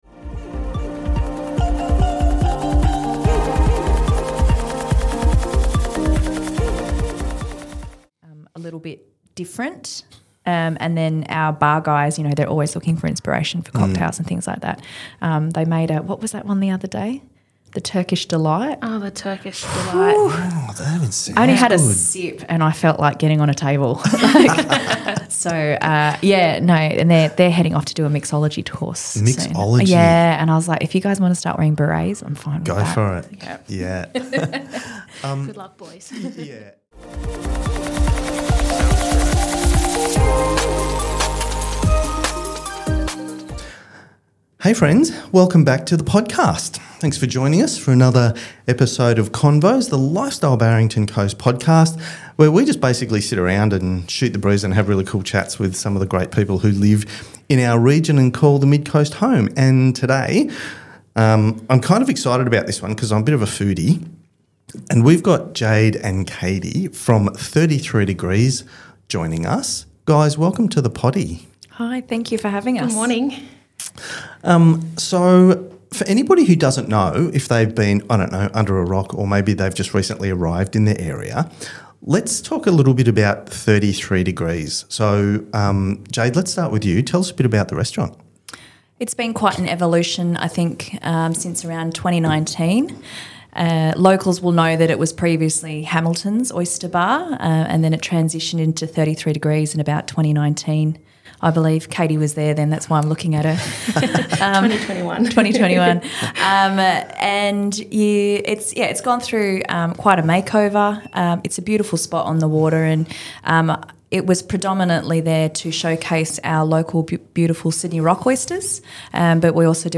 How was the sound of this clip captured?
A few laughs, good conversation, and a whole lot fo talk about food was on the menu for this episode of Convos.